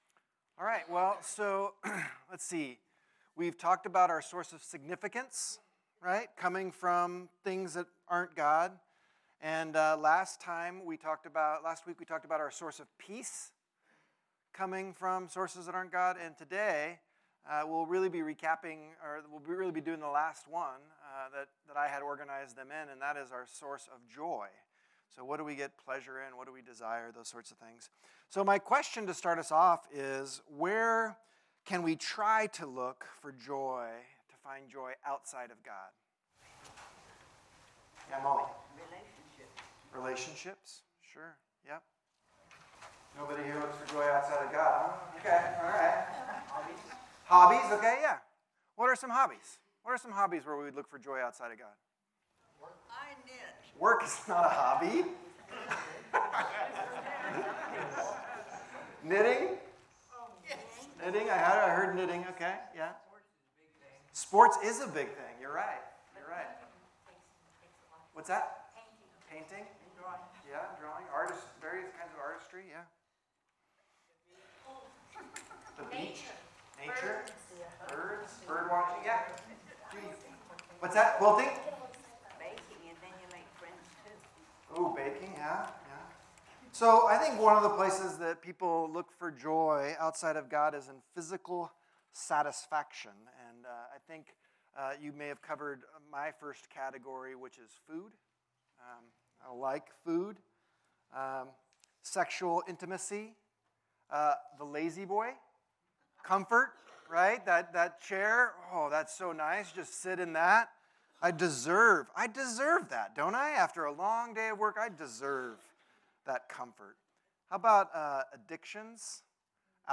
Series: Broken Cisterns Type: Sunday School